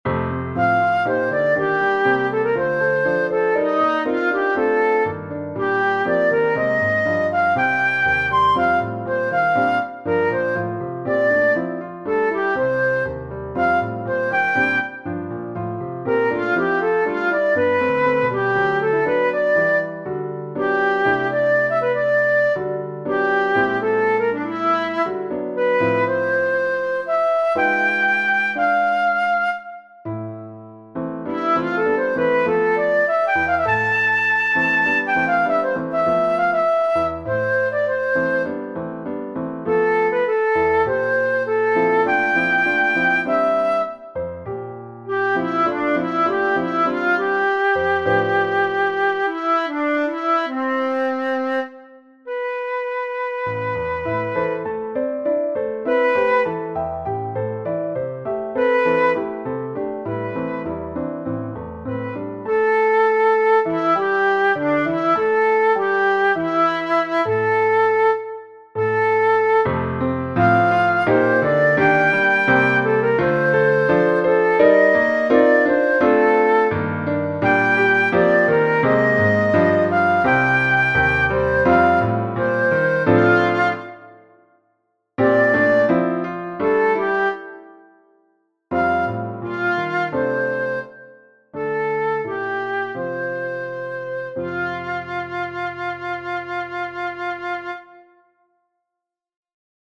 Two players - no more, no less.
Flute and Piano Number 2 MS